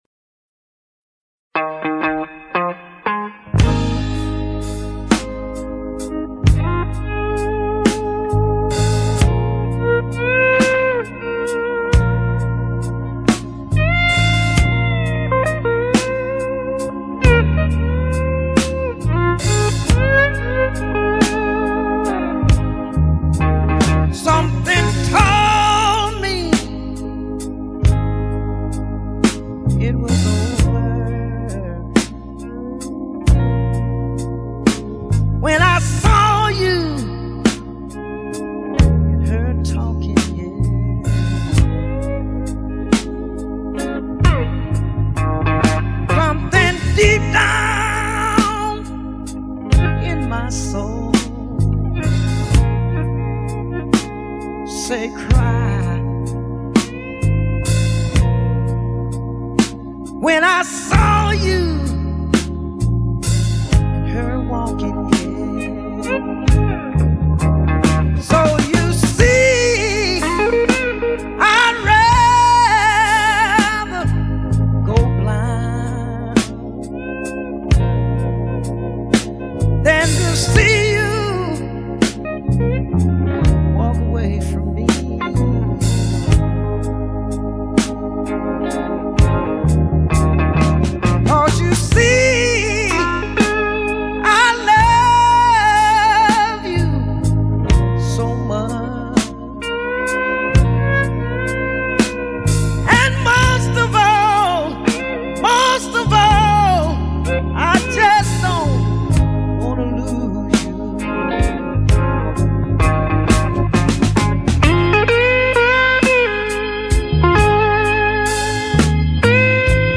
Pop/★Blues Paradise★